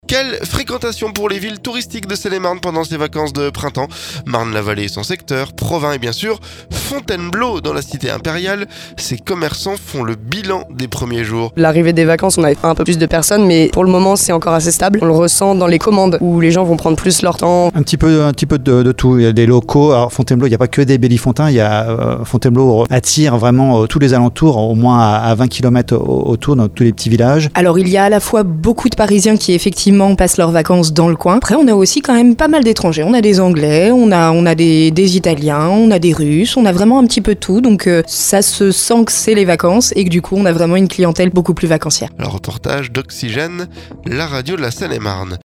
FONTAINEBLEAU - Les commerçants parlent de la fréquentation du début des vacances
Dans la cité impériale, ces commerçants font le bilan des premiers jours.